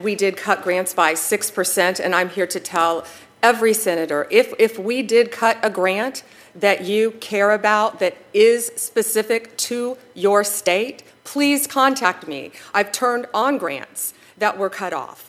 A senate appropriations committee heard testimony from US Attorney General Pam Bondi on Wednesday about Department of Justice budgets for the coming year.
AG Bondi responded quickly, saying the funding is in process…